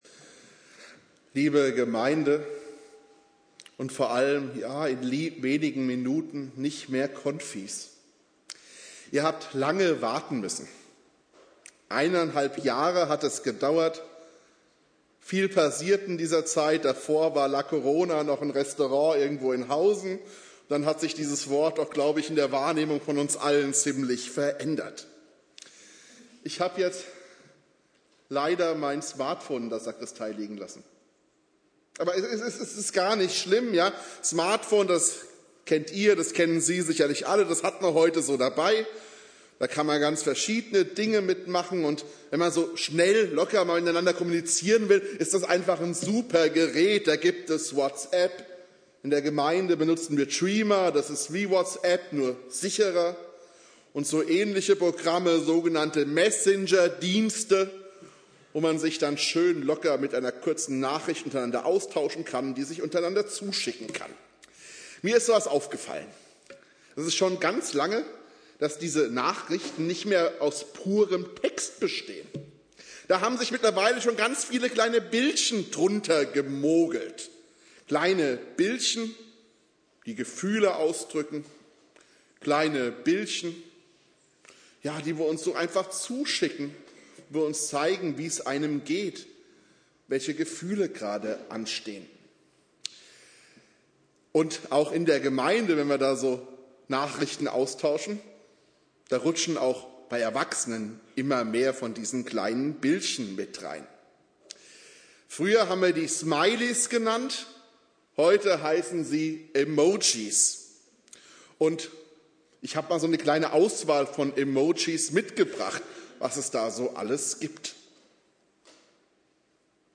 Inhalt der Predigt: Predigt zur Konfirmation 2021 (Teil 2) Dauer: 11:07 Abspielen: Ihr Browser unterstützt das Audio-Element nicht.